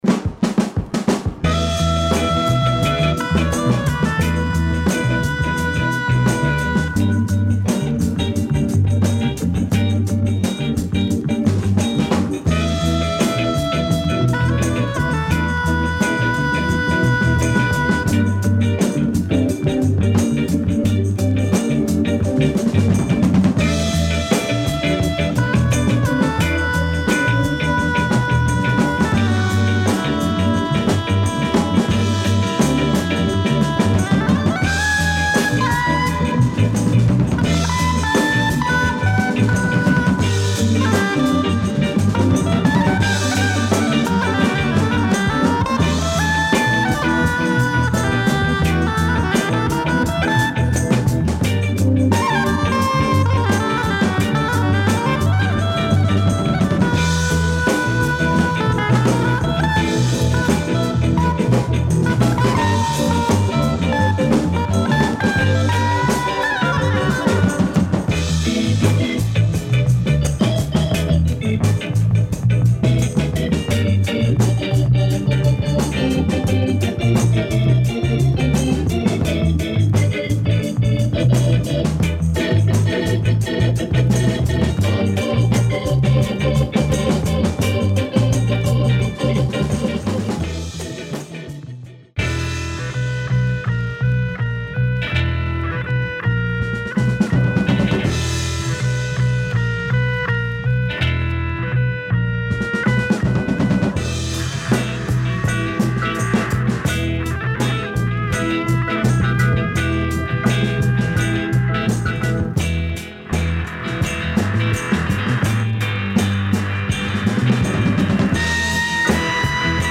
top psych pop groove with organ and disto guitar
bossa jazz
delightful pop with flute, organ or sax
have a jazz feel with vibraphone